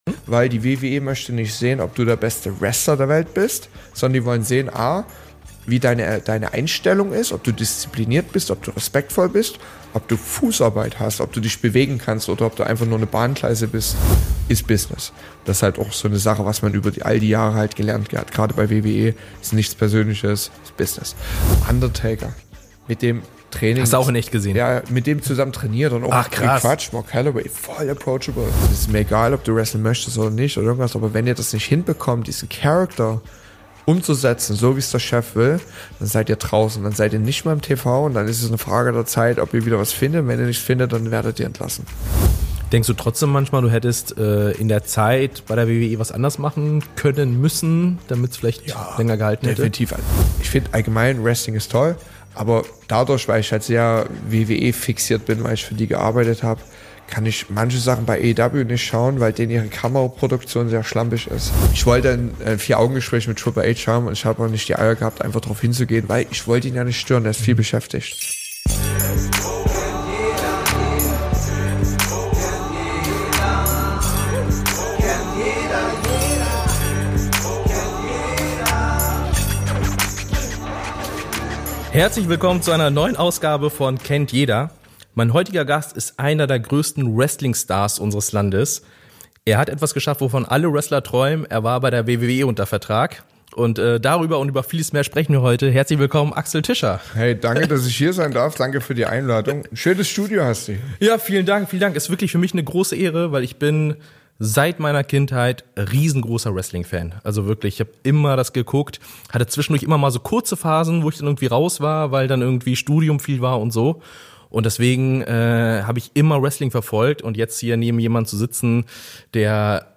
Beschreibung vor 6 Monaten In der neuen Folge von „Kennt jeder“ ist Axel Tischer zu Gast – ehemaliger WWE-Wrestler, bekannt durch seine Zeit bei der WWE, wo er als Alexander Wolfe auftrat.
Ein ehrliches, intensives Gespräch über große Träume, Niederlagen, Comebacks und die Leidenschaft, für die er alles gegeben hat.